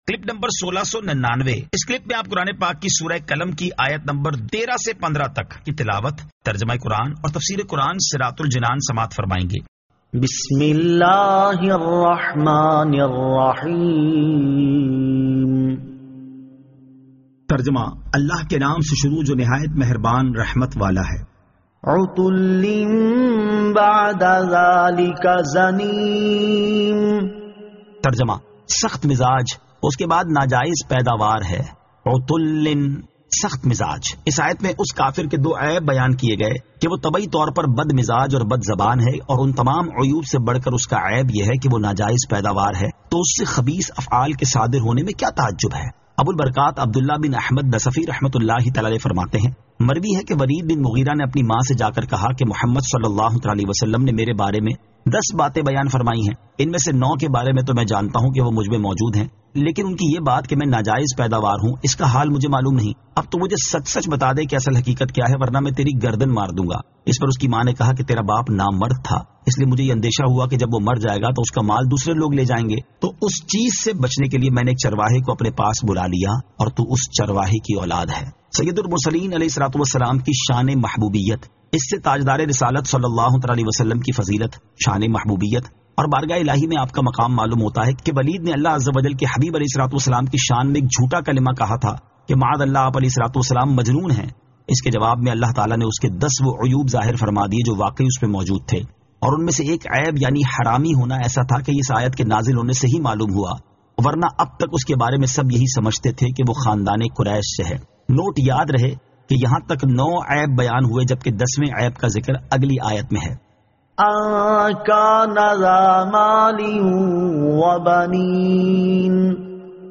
Surah Al-Qalam 13 To 15 Tilawat , Tarjama , Tafseer